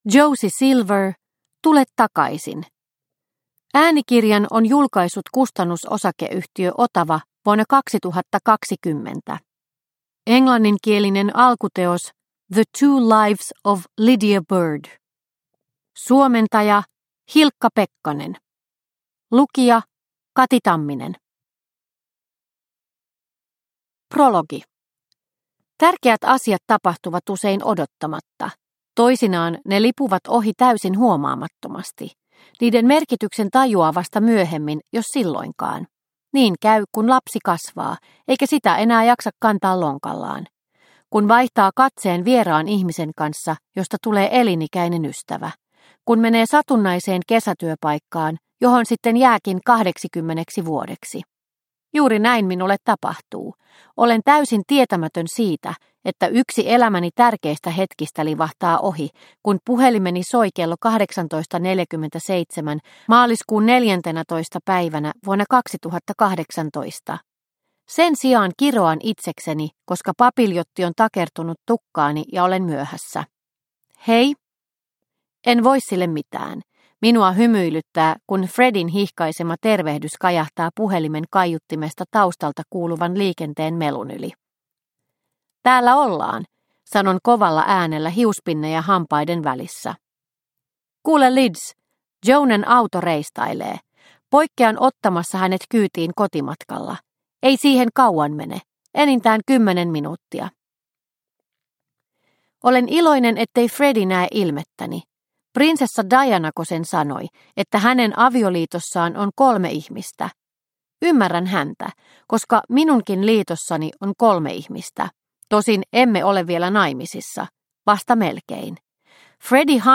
Tule takaisin – Ljudbok – Laddas ner